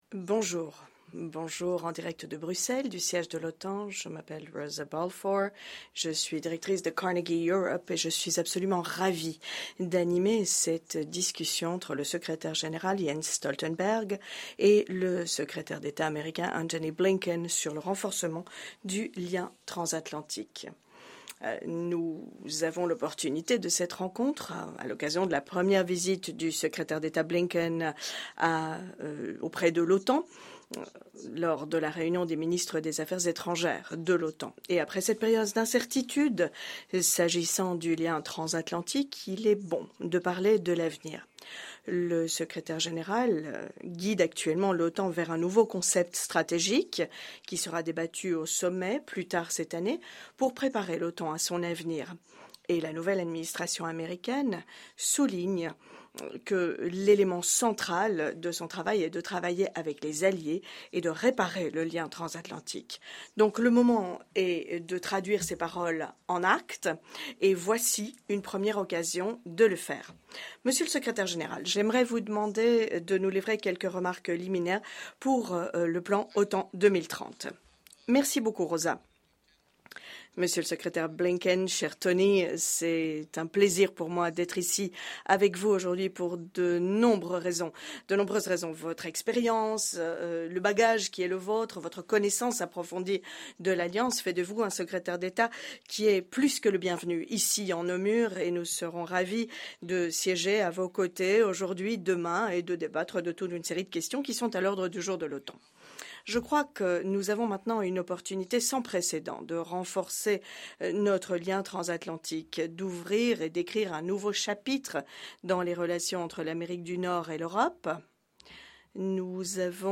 A conversation between NATO Secretary General Jens Stoltenberg and the US Secretary of State Antony Blinken